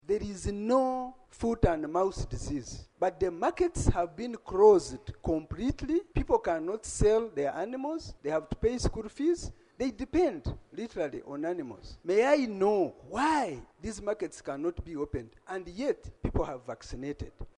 The matter was raised by Hon. Naome Kabasharira, the Rushenyi County Member of Parliament during plenary sitting chaired by Speaker, Anita Among on Thursday, 03 October 2024.